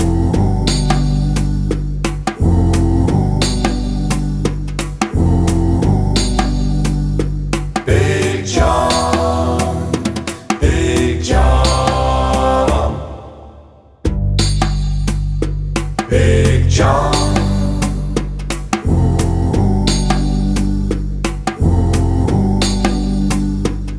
no Backing Vocals With Intro Country